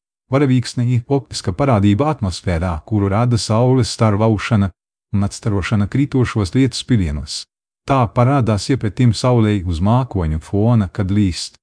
Text-to-Speech
tts